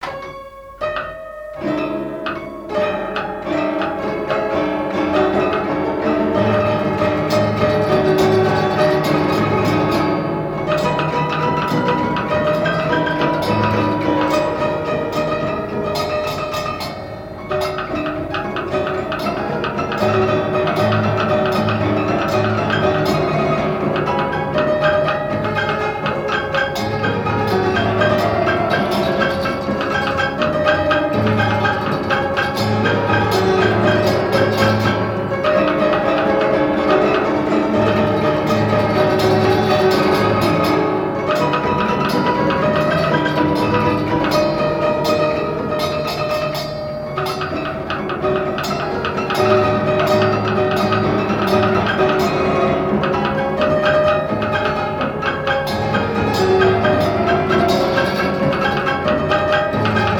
air n° 2 du piano mécanique
danse : marche
Répertoire du piano mécanique
Pièce musicale inédite